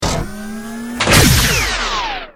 battlesuit_hugelaser.ogg